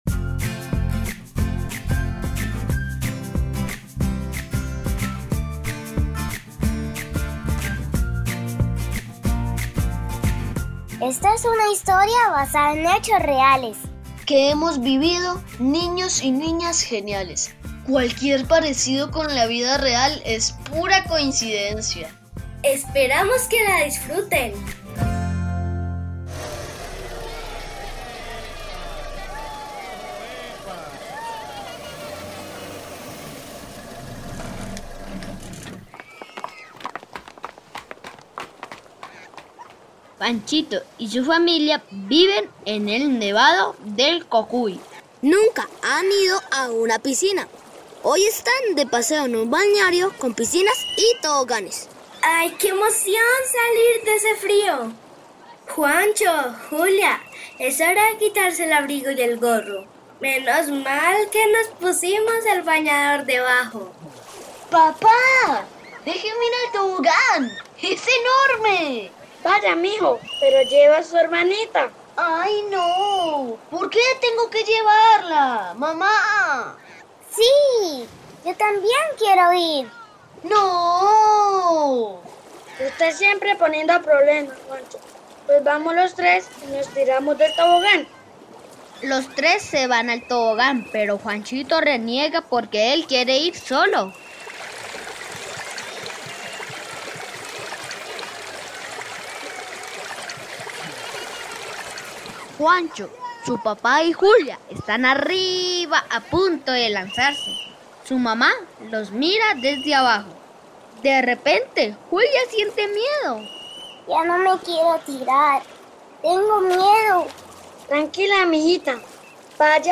GENIA_T01_CUENTOS_EN_RADIOTEATRO_C03_ALTA.mp3